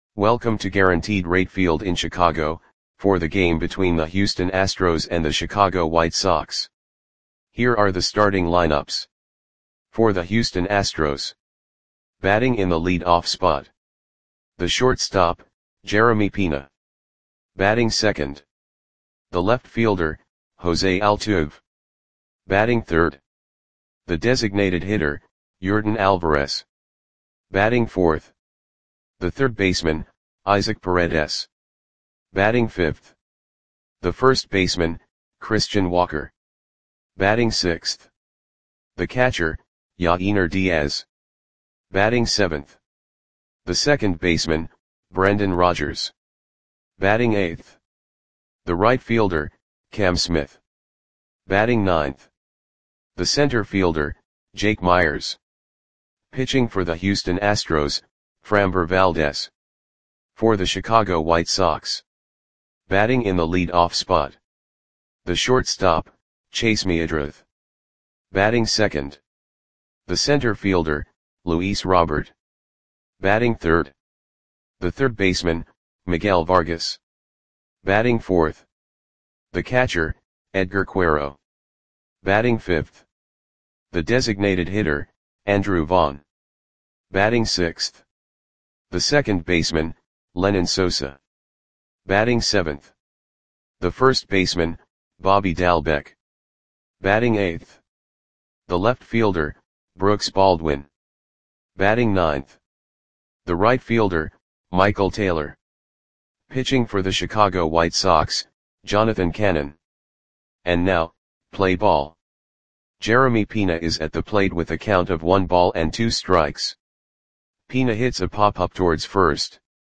Audio Play-by-Play for Chicago White Sox on May 2, 2025
Click the button below to listen to the audio play-by-play.